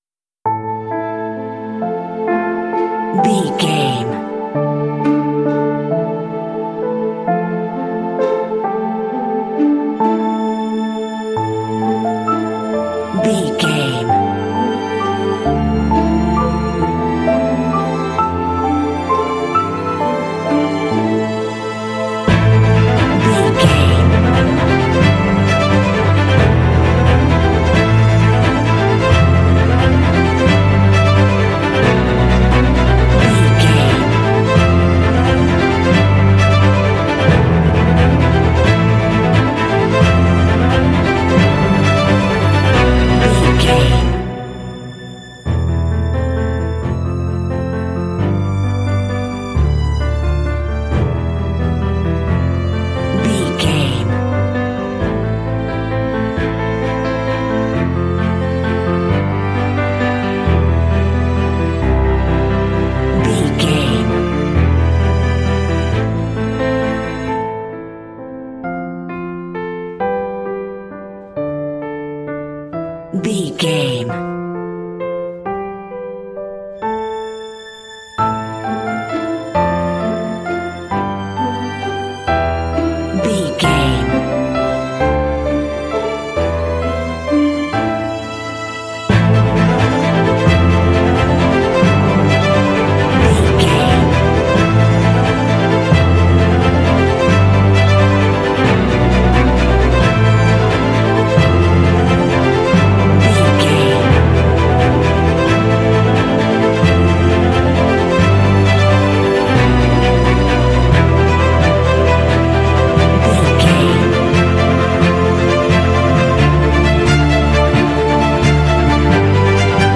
Aeolian/Minor
melancholy
piano
strings
orchestral
cinematic